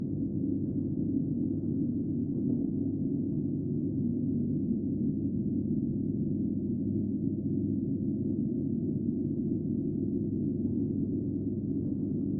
白噪声楼道2.wav